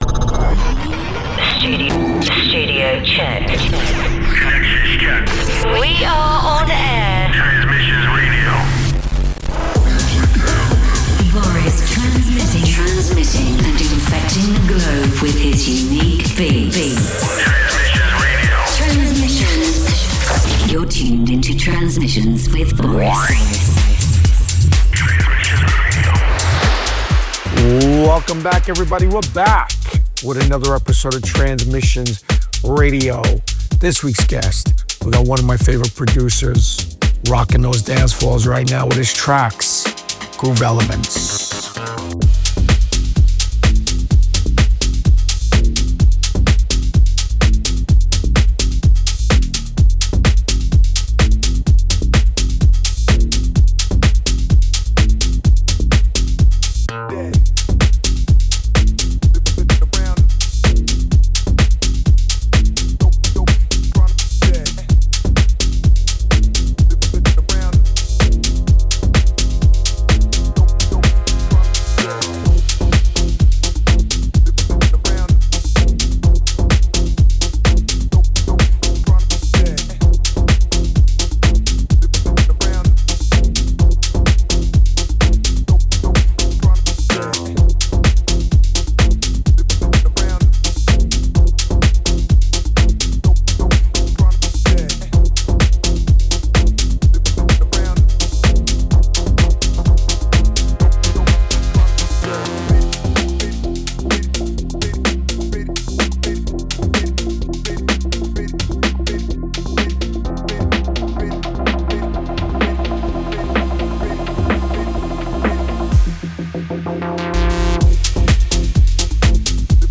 techno music